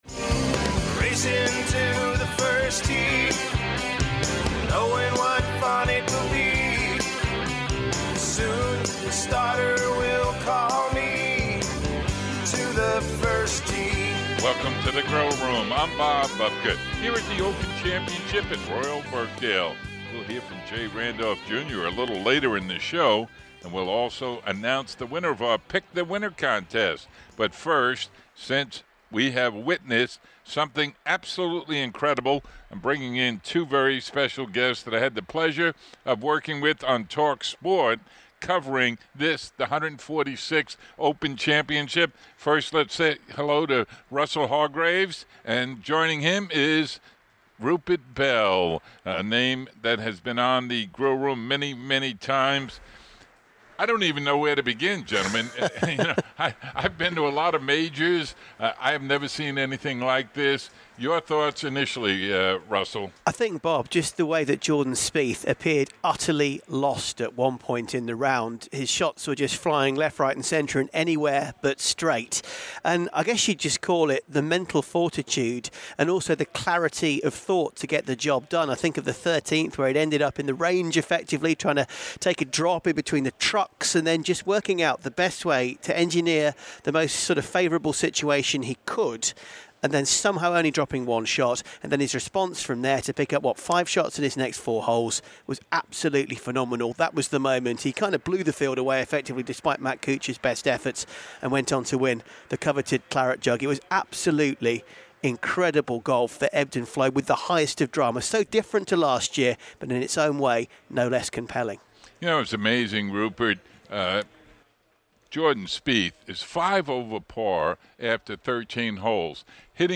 Congratulations to Jordan Spieth - what an amazing performance. Feature Interviews